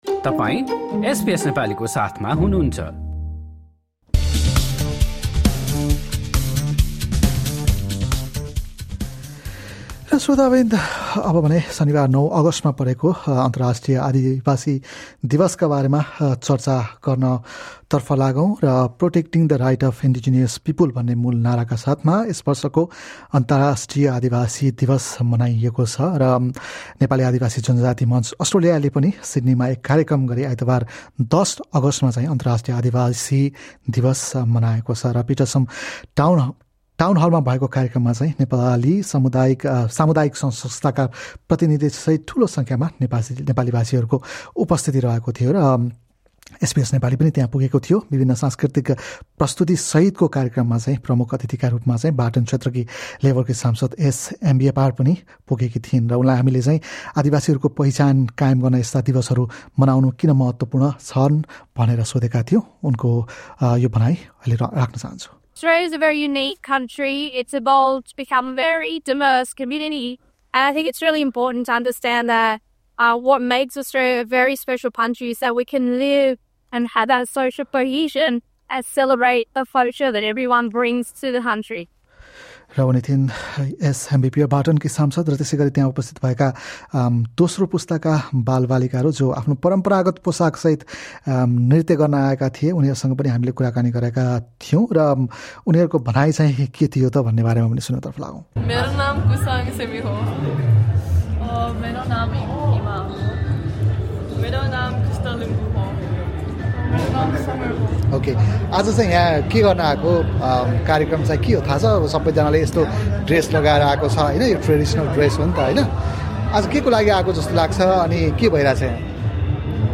कार्यक्रममा पुगेका अतिथि, दर्शक र कलाकारहरूसँग एसबीएस नेपालीले गरेको कुराकानी सुन्नुहोस्।